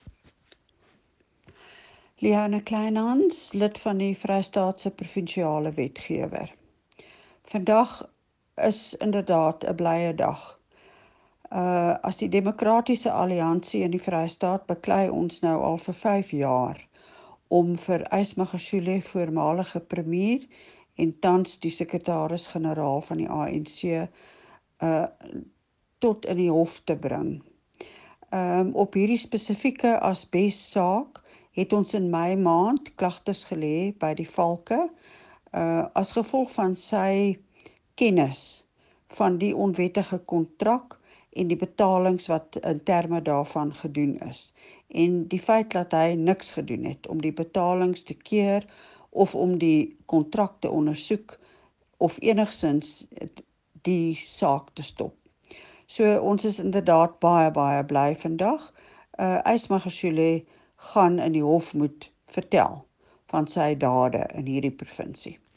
Note to Editors: Please find the attached soundbites in
Afrikaans by Leona Kleynhans MPL, Member of the Official Opposition in the Free State Legislature.